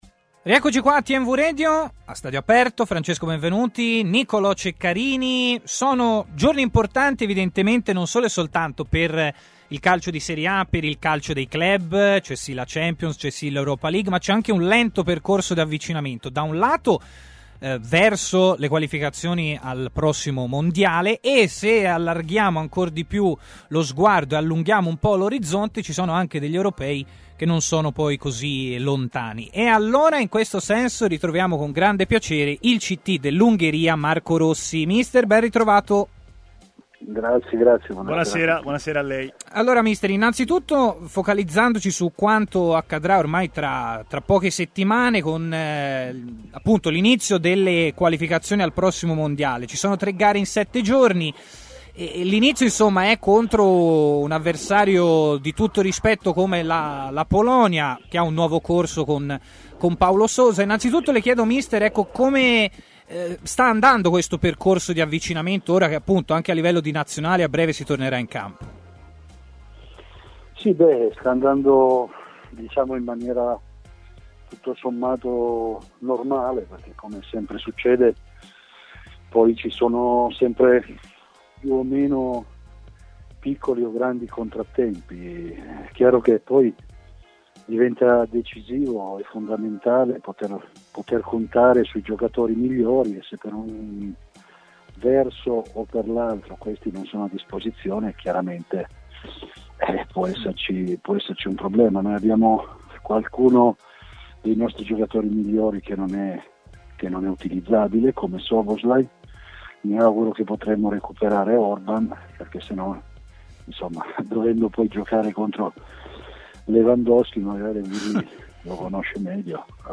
Marco Rossi, ct dell'Ungheria, ha parlato a Stadio Aperto, trasmissione di TMW Radio